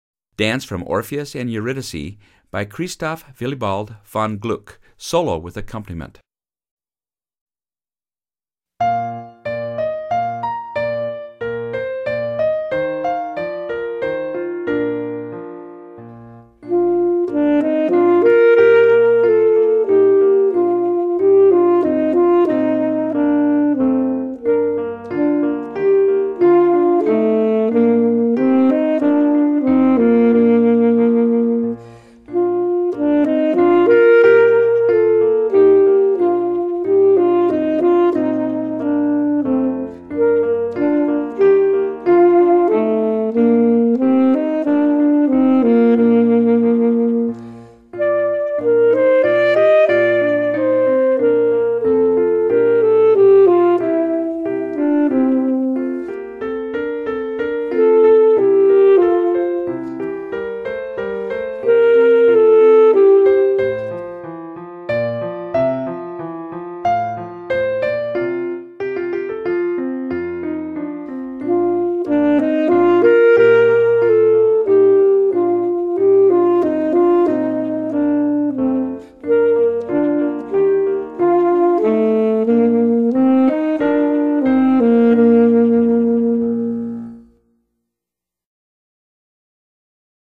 Solo with Piano Accompaniment – Performance Tempo